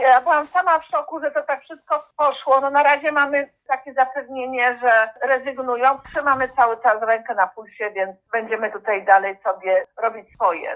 Zainteresowani, jak przyznaje jedna z organizatorek protestu, podchodzą do tego stanowiska z rezerwą.